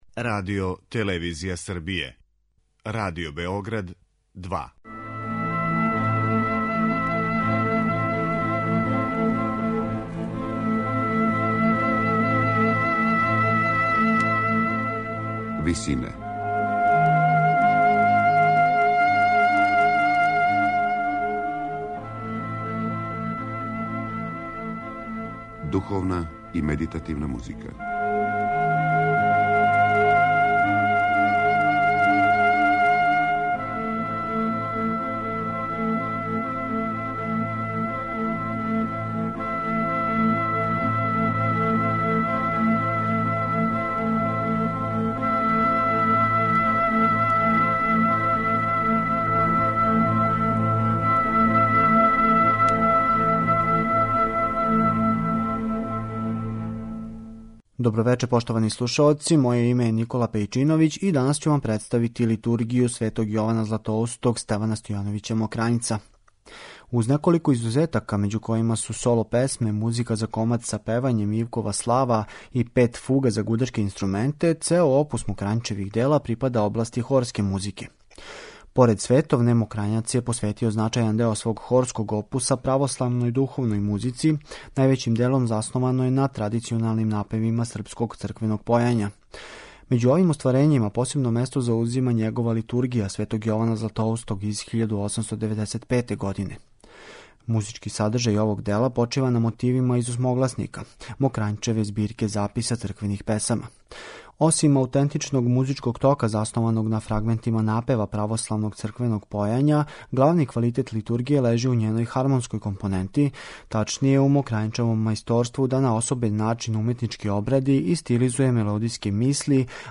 Емисија о духовној и медитативној музици